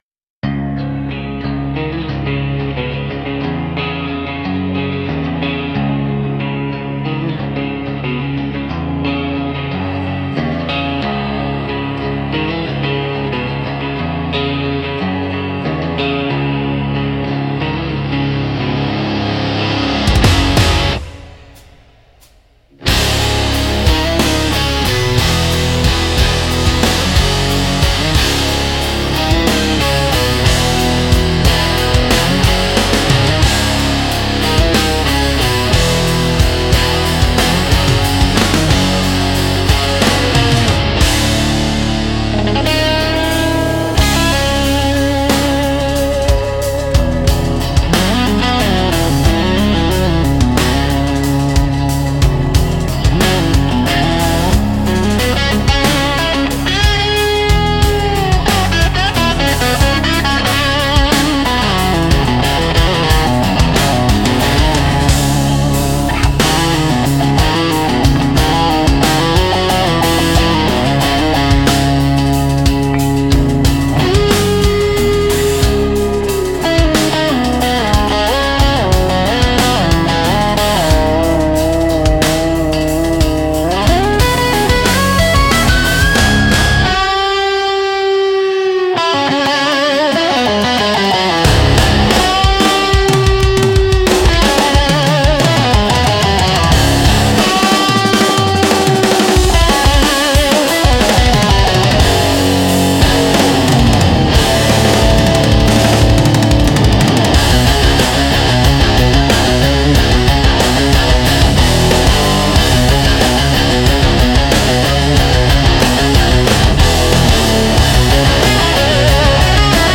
Instrumental - Pull-Offs at the Edge of Town